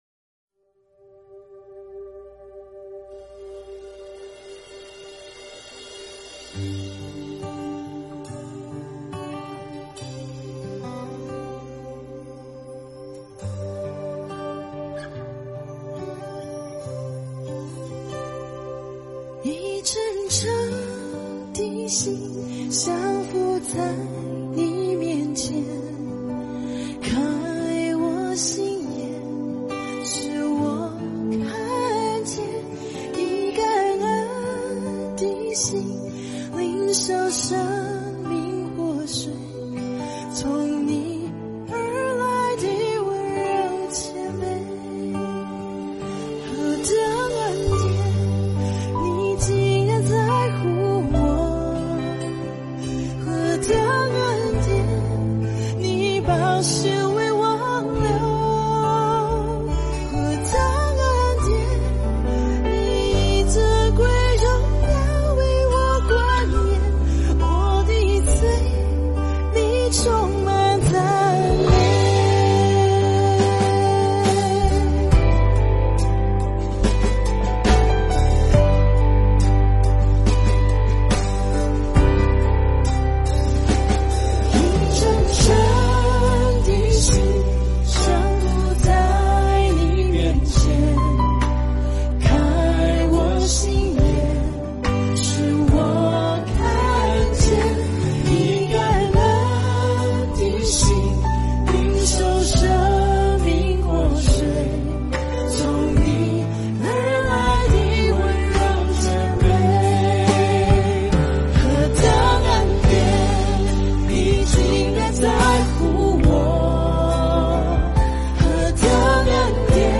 赞美诗 | 何等恩典